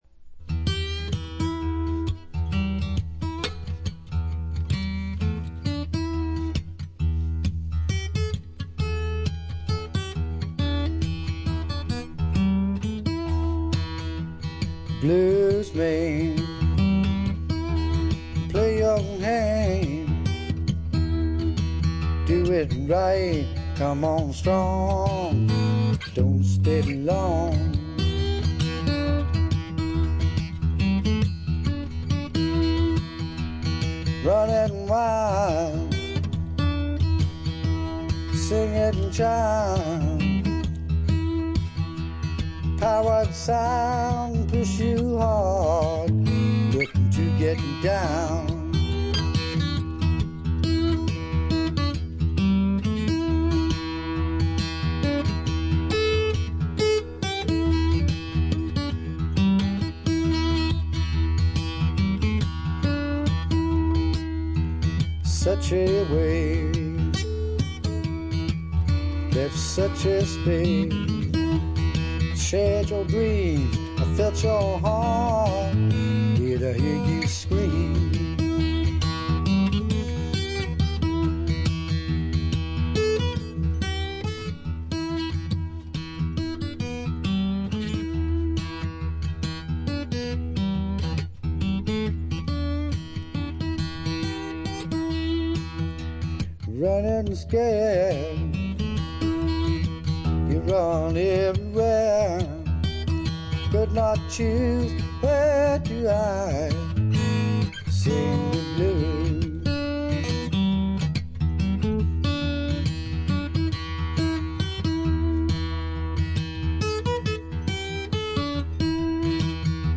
アルバムのラストは、このアルバム唯一のアコースティック・ギターの弾き語りによるフォーク・ブルース・ナンバー。